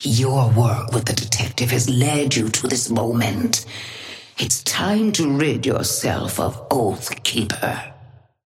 Sapphire Flame voice line - Your work with the detective has led you to this moment.
Patron_female_ally_ghost_oathkeeper_5b_start_01.mp3